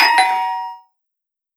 notif_message.37f478bf.wav